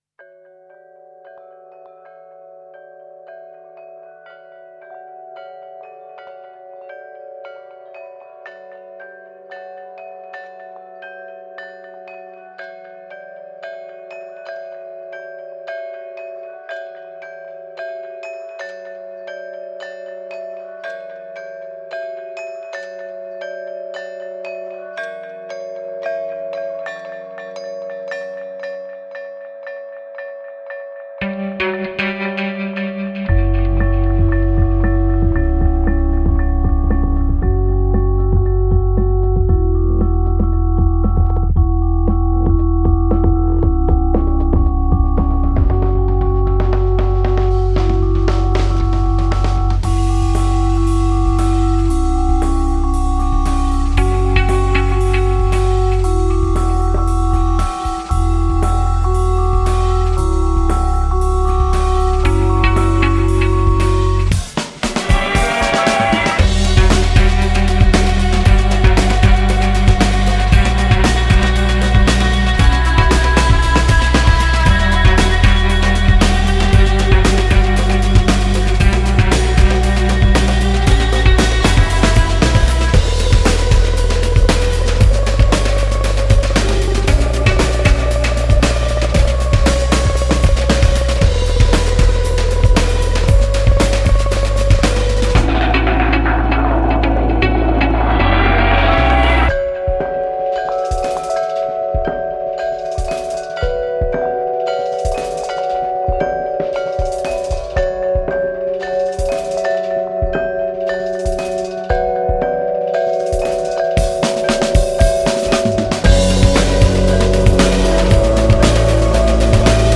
موسیقی اینسترومنتال
موسیقی جاز